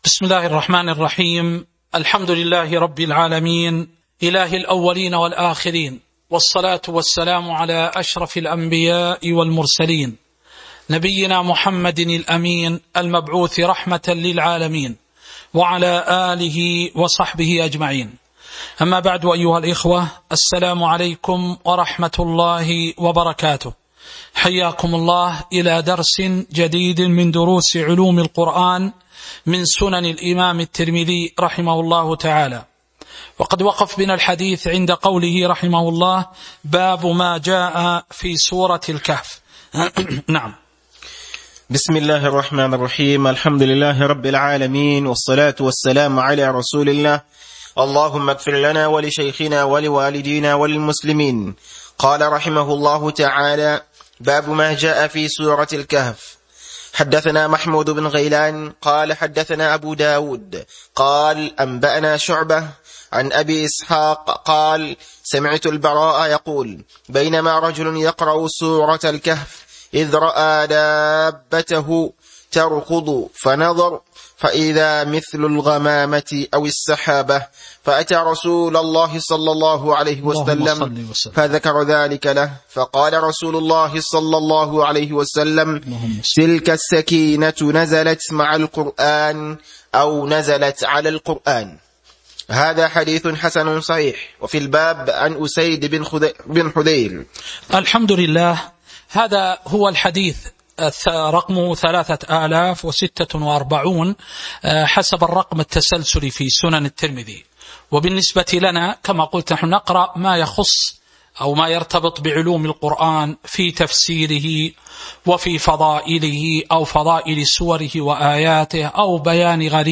تاريخ النشر ١٣ محرم ١٤٤٣ هـ المكان: المسجد النبوي الشيخ